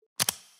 Звуки сообщений iPhone
iPhone 13 — звук отключения экрана